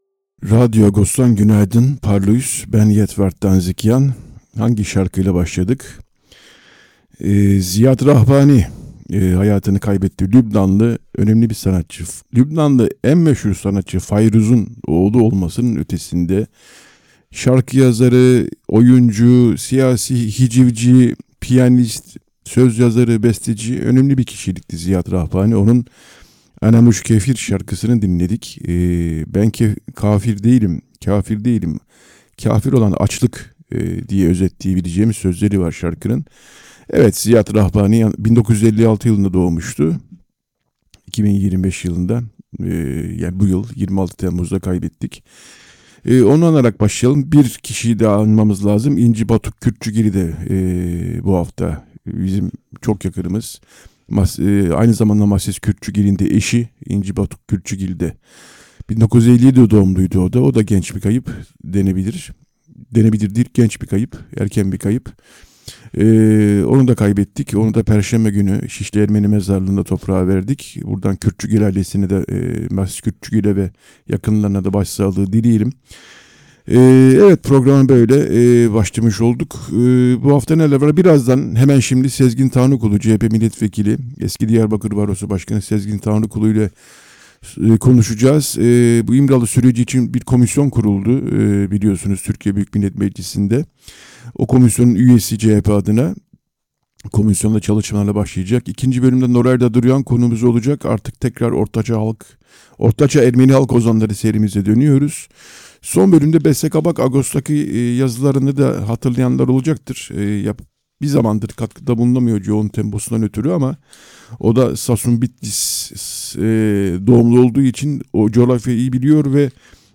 Komisyon ilk toplantısını 5 Ağustos'ta yapacak. 2 Ağustos Cumartesi sabahı Apaçık Radyo/Radyo Agos programına katılan CHP milletvekili ve özel komisyonun üyesi Sezgin Tanrıkulu ile süreç ve komisyonun çalışmalarına ilişkin beklentileri konuştuk.